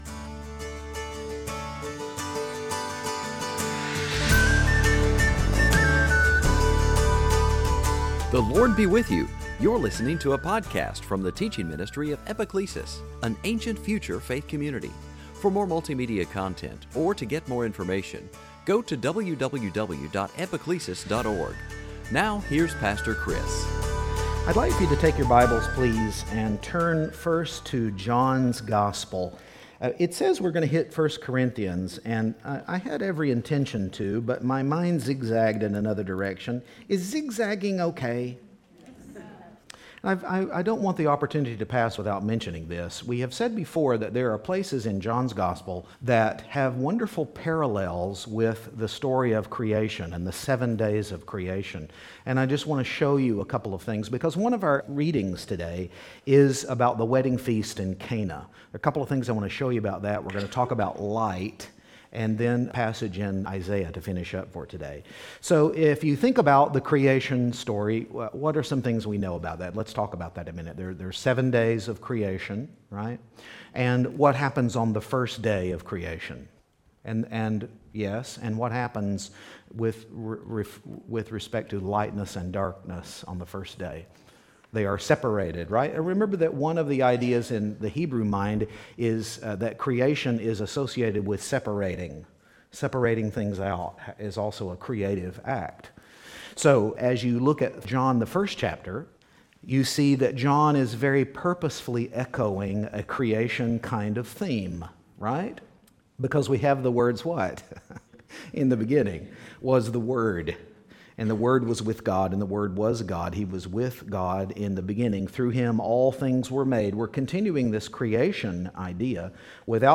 Series: Sunday Teaching Some passages in Isaiah